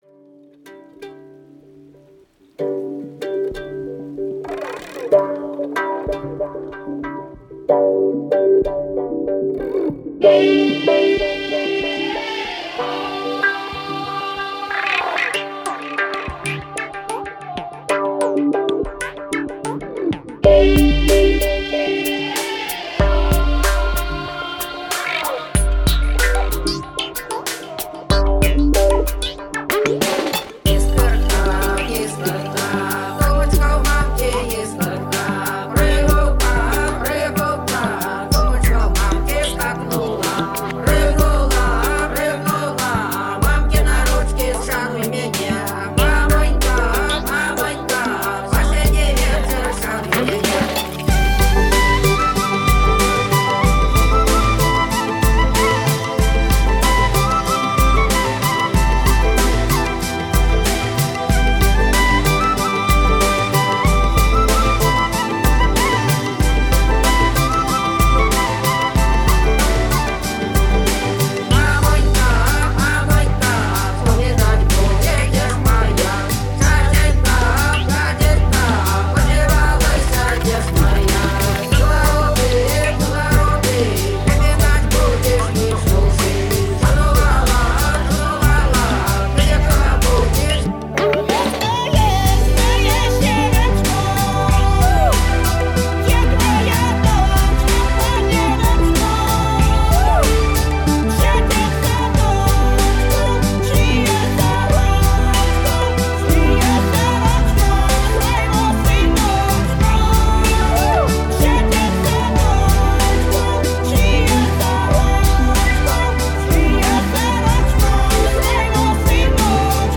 электронная поп-группа, работает в этническом стиле.
Genre: Folk